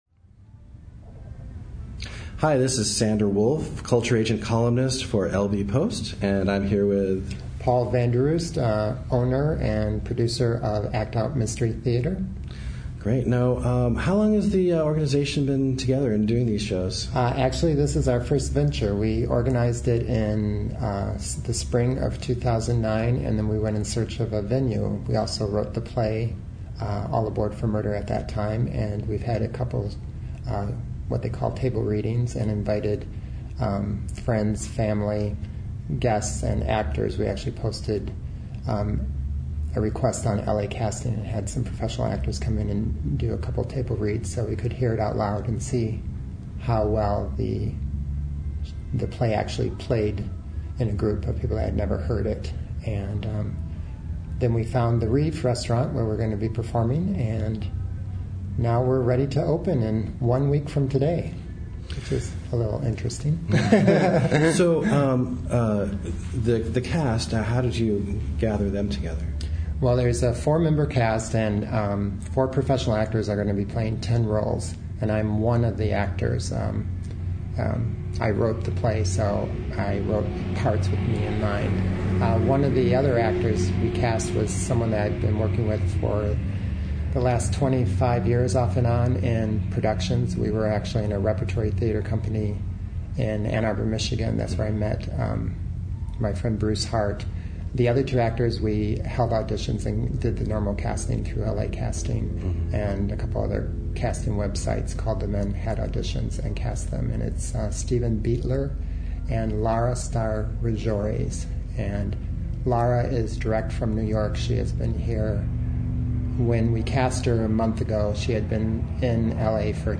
He took some time to speak with me about the new dinner theatre production, and reveals plans for what’s next.